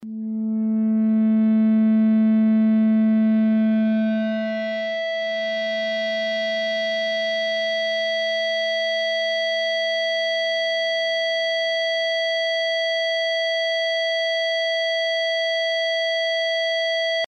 Sounds of a microphone download and listen online
• Category: Microphone